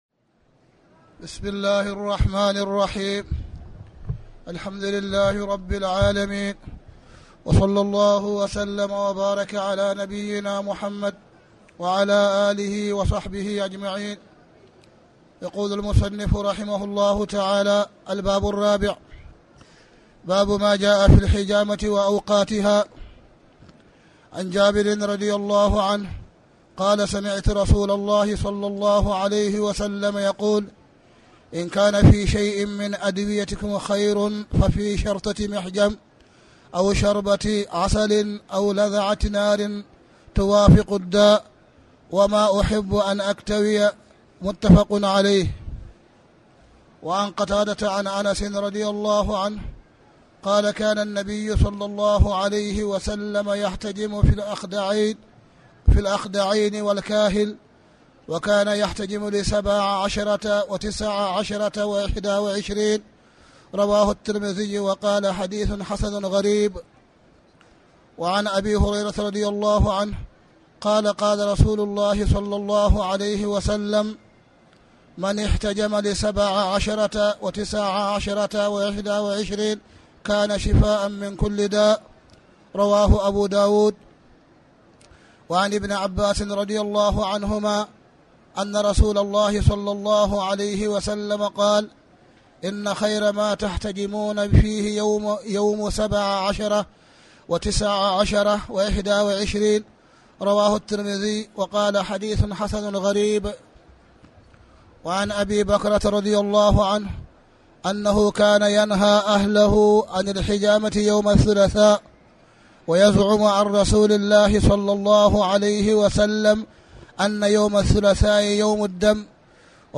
تاريخ النشر ١٥ رمضان ١٤٣٩ هـ المكان: المسجد الحرام الشيخ: معالي الشيخ أ.د. صالح بن عبدالله بن حميد معالي الشيخ أ.د. صالح بن عبدالله بن حميد كتاب الطب